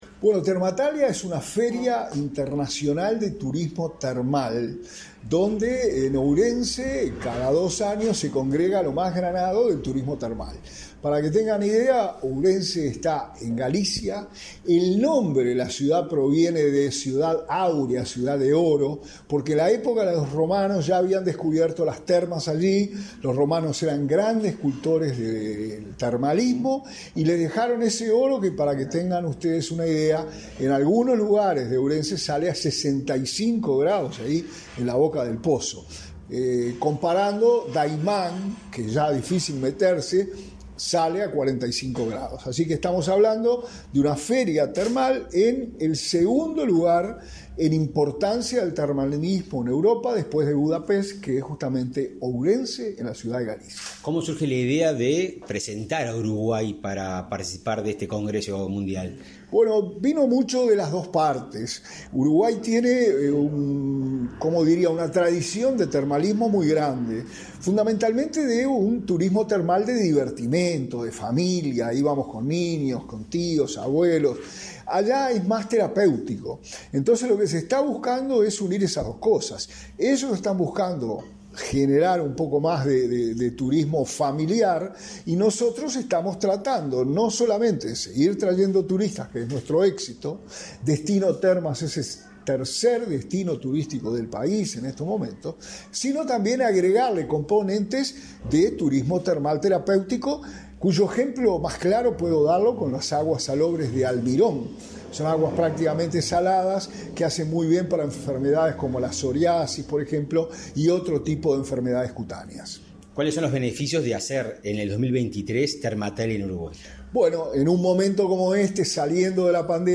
Entrevista al subsecretario de Turismo, Remo Monzeglio